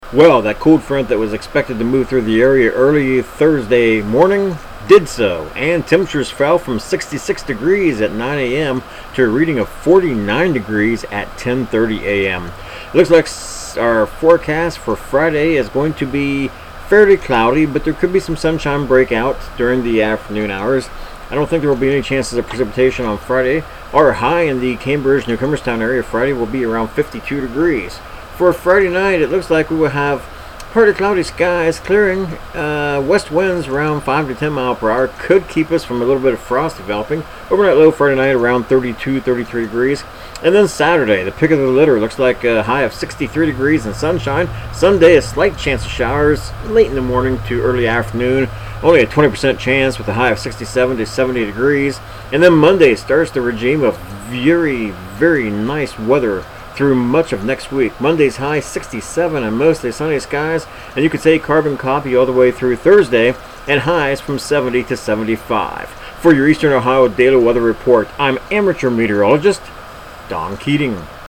Tags: Weather Ohio Newcomerstown Forecast Report